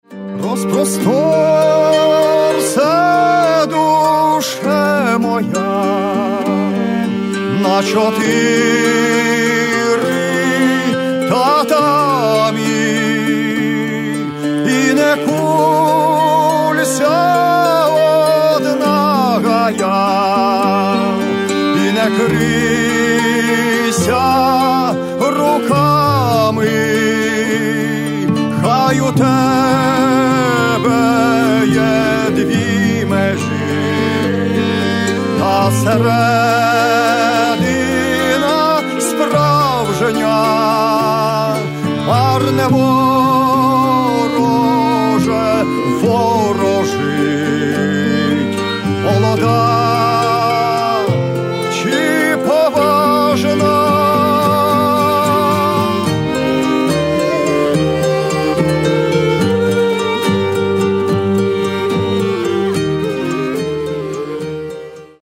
Каталог -> Народная -> Старинная музыка